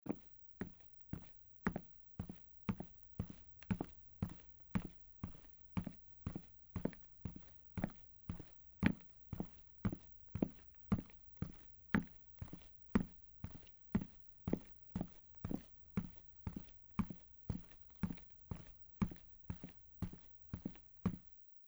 较硬的路面快步走-小声YS070525.mp3
通用动作/01人物/01移动状态/01硬地面/较硬的路面快步走-小声YS070525.mp3
• 声道 立體聲 (2ch)